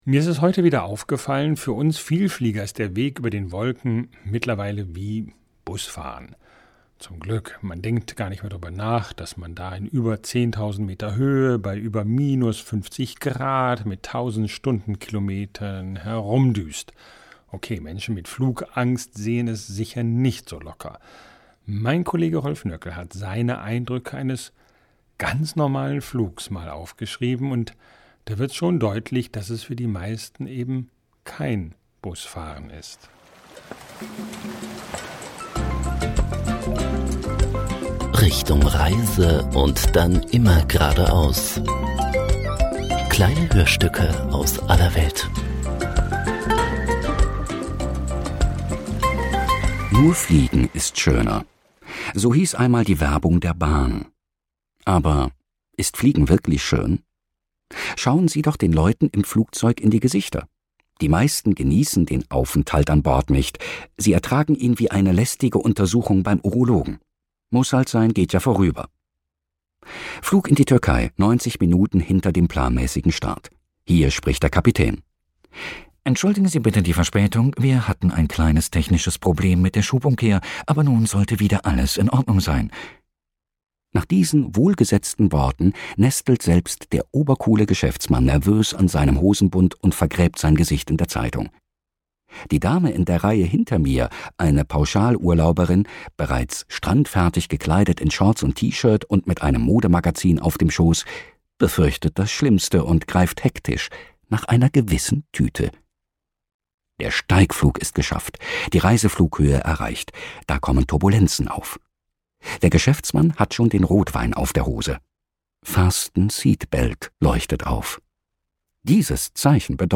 Hörstück